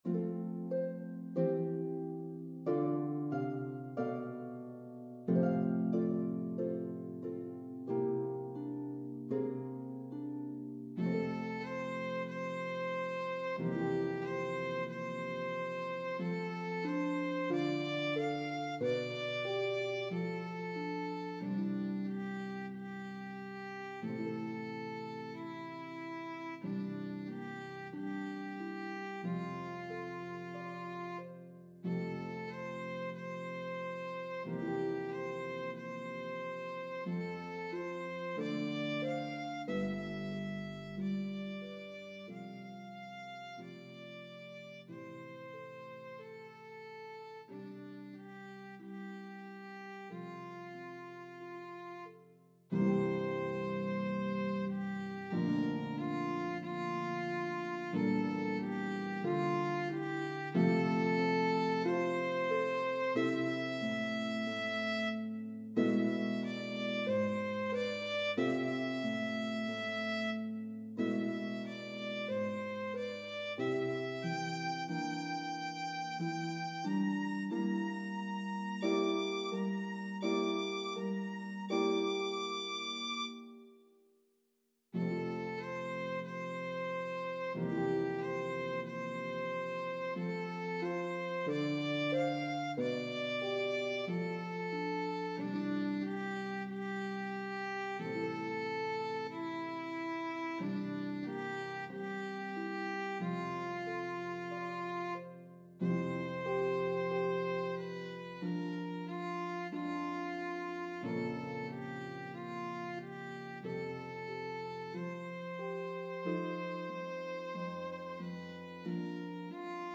The warmth of this duet will enchant your audience.